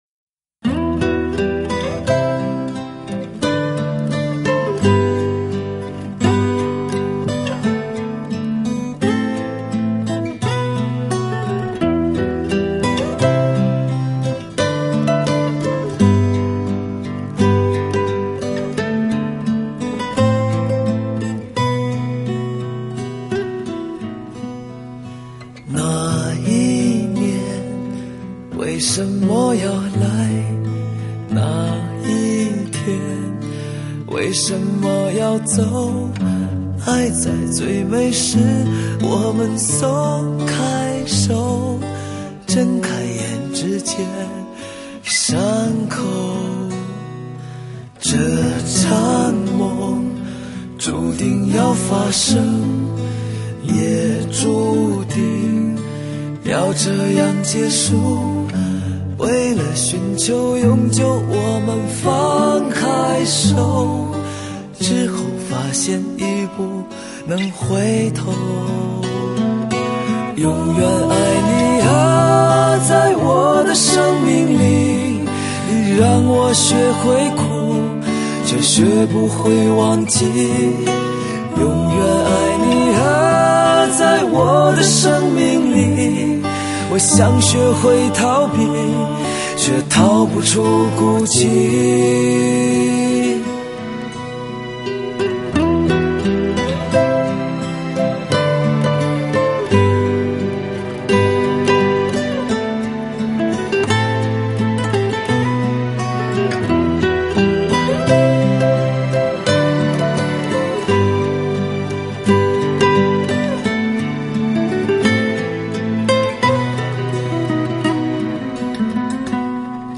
恢弘的管弦乐、时尚的电子乐、张扬的硬摇滚、朴素的